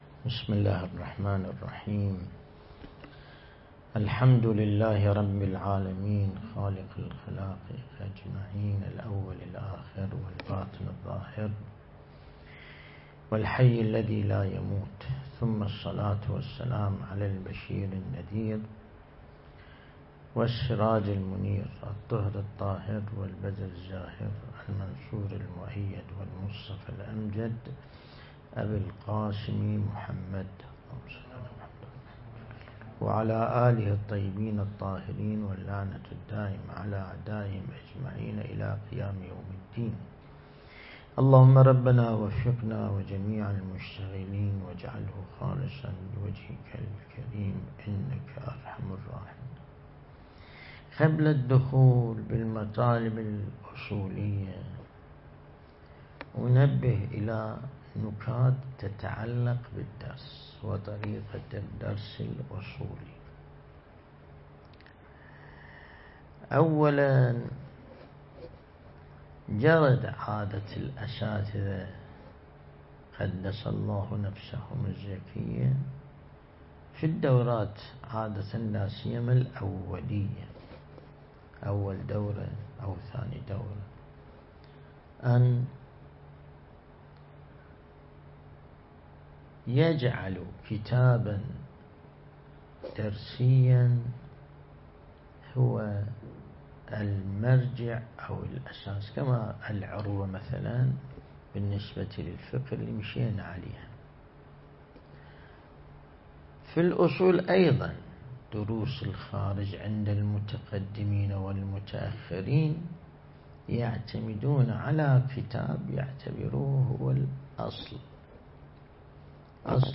درس البحث الخارج الأصول (1)
النجف الأشرف